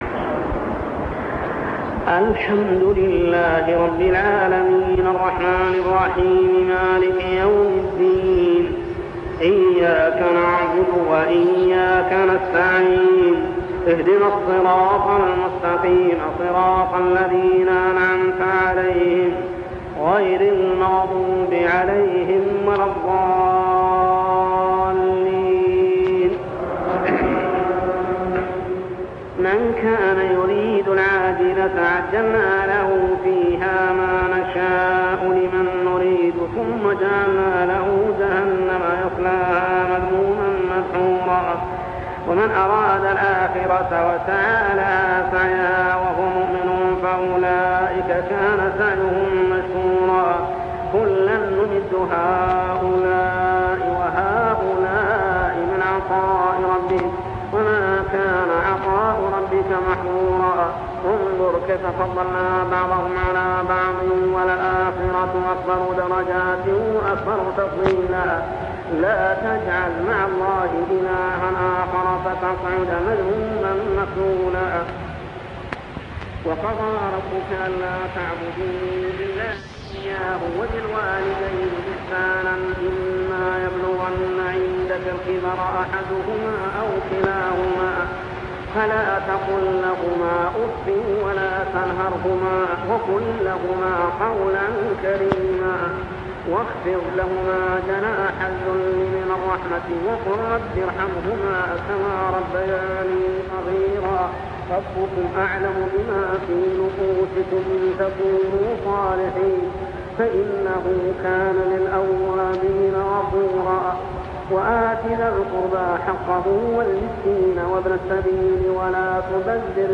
صلاة التراويح عام 1401هـ سورة الإسراء 18-96 | Tarawih prayer Surah Al-Isra > تراويح الحرم المكي عام 1401 🕋 > التراويح - تلاوات الحرمين